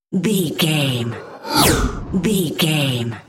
Airy whoosh pass by
Sound Effects
pass by
sci fi
vehicle